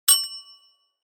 دانلود آهنگ دوچرخه 2 از افکت صوتی حمل و نقل
دانلود صدای دوچرخه 2 از ساعد نیوز با لینک مستقیم و کیفیت بالا
جلوه های صوتی